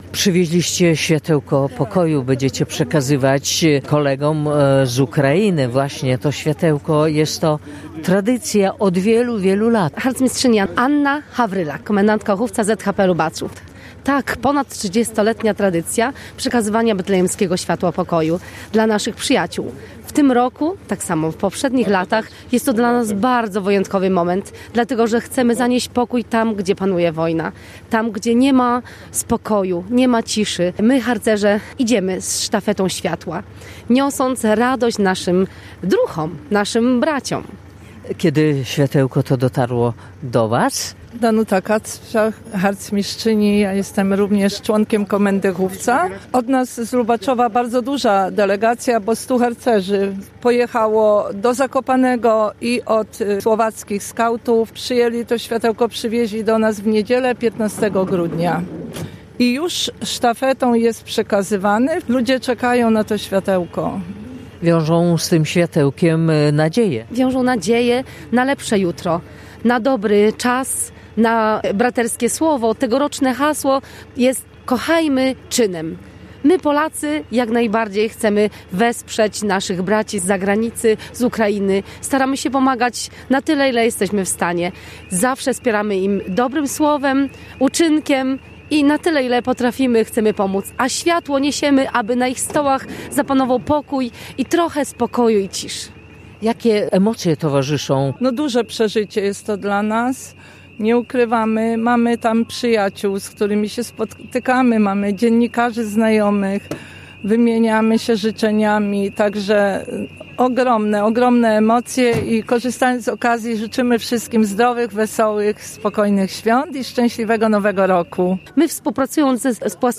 Betlejemskie światełko pokoju zostało przekazane do powiatu jaworowskiego w Ukrainie. Uroczystość odbyła się na przejściu granicznym w Korczowej.